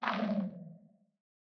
sculk_clicking_stop3.ogg